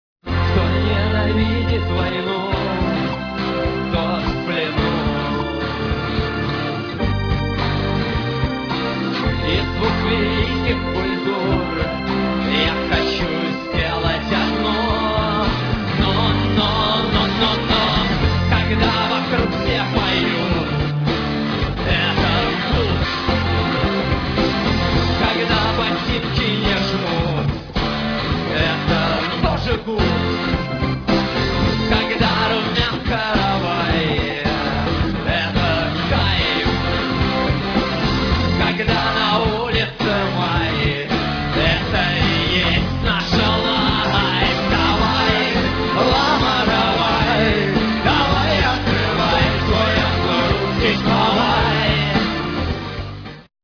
ДК им. Горбунова (1996)
VIDEO, stereo
фрагмент песни ( 55 сек.)